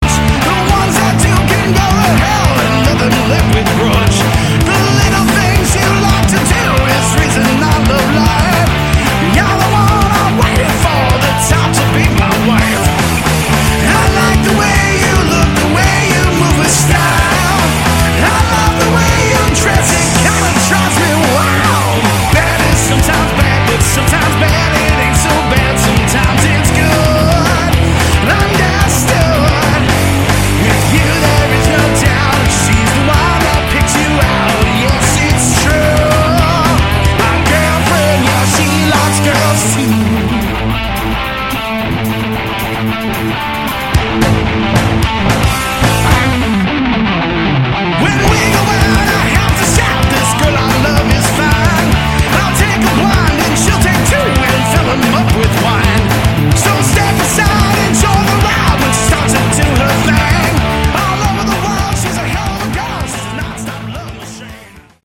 Category: Melodic Rock / AOR
guitar, bass, keyboards, drums
vocals, saxophone
backing vocals